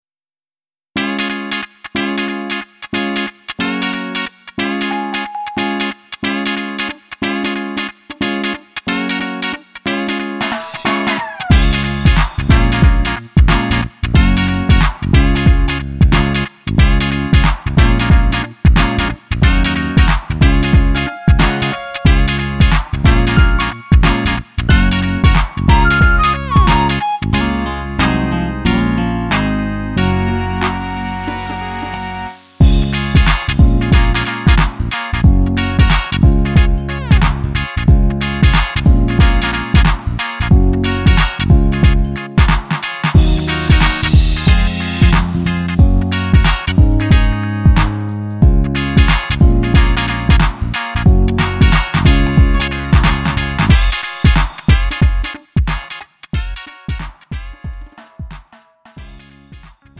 음정 -1키 3:02
장르 가요 구분